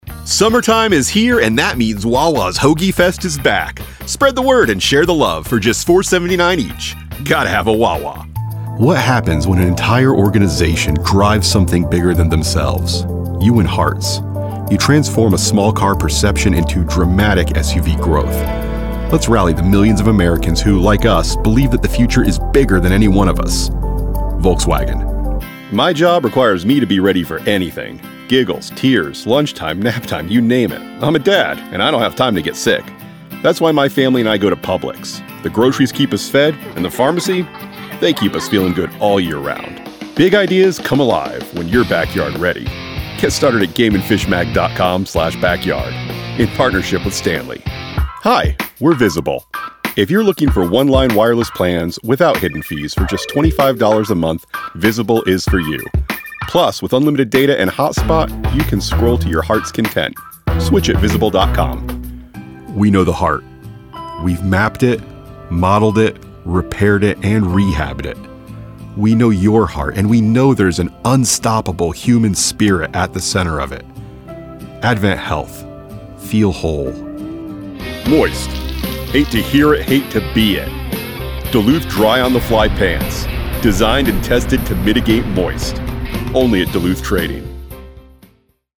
voice built for impact delivery built for clarity presence built for connection
Deep. Rich. Unmistakably Real. Play My Commercial Demo